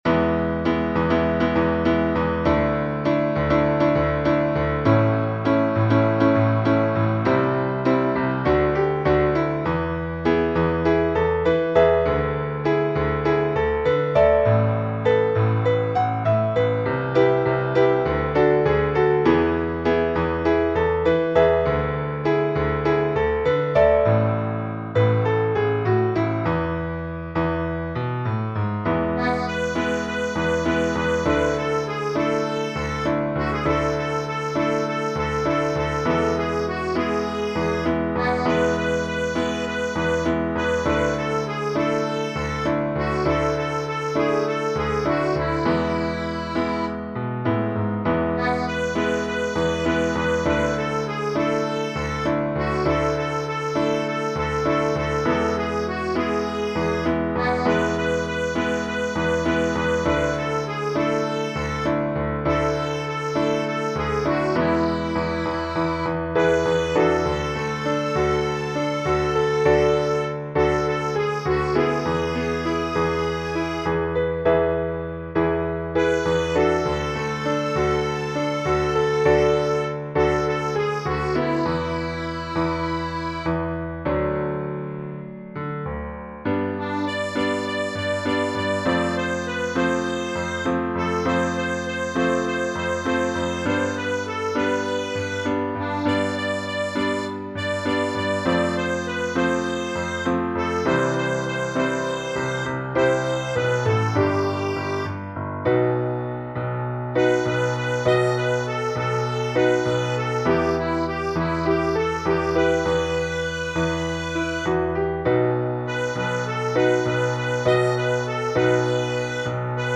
伴奏音源